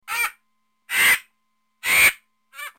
دانلود آهنگ کرکس برای کودکان از افکت صوتی انسان و موجودات زنده
دانلود صدای کرکس برای کودکان از ساعد نیوز با لینک مستقیم و کیفیت بالا
جلوه های صوتی